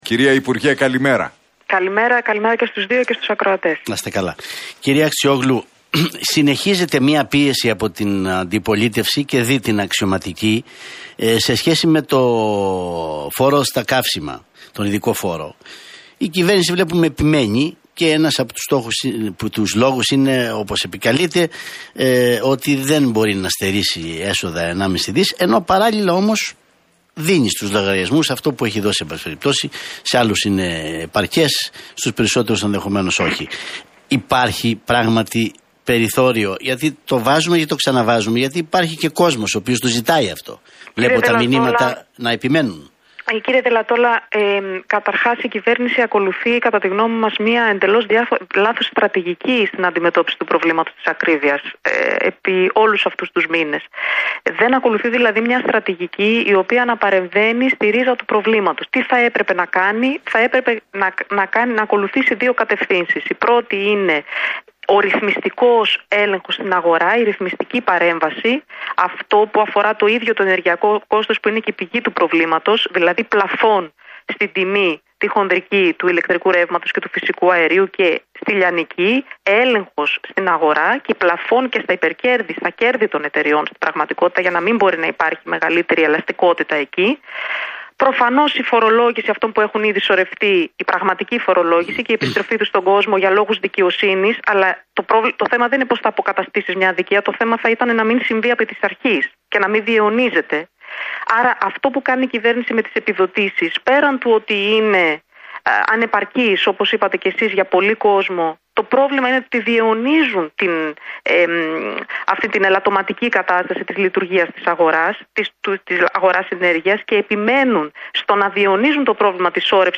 Αχτσιόγλου στον Realfm 97,8: Οικονομική ασφυξία με την κυβέρνηση να διαιωνίζει το πρόβλημα